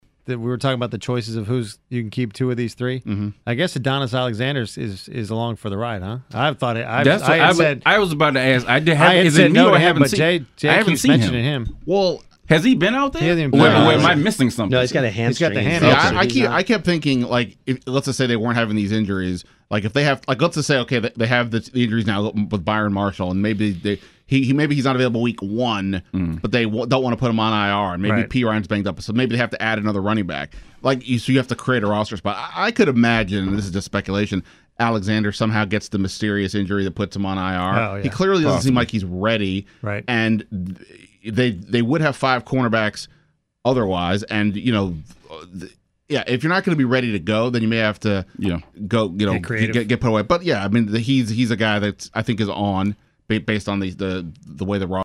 • It’s pretty good as long as people aren’t stepping over each other verbally.